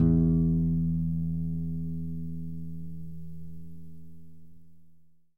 我最好的尼龙吉他弹奏
描述：雅马哈C40古典吉他指弹